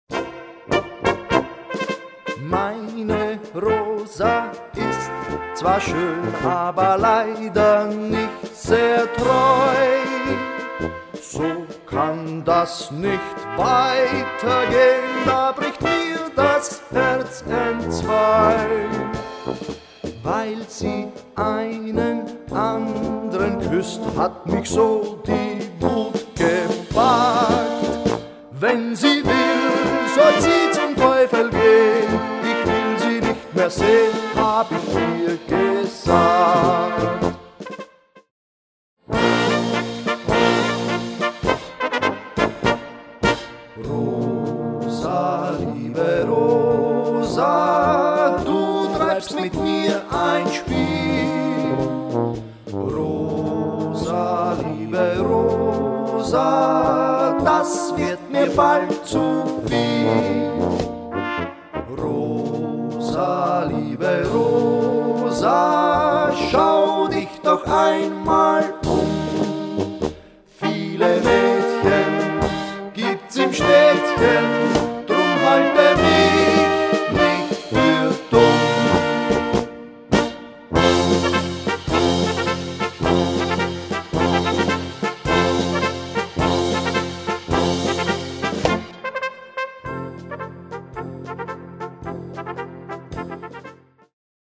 Gattung: Polka
Besetzung: Blasorchester
Am besten kommt dies im 2. Teil des Trios zum Vorschein.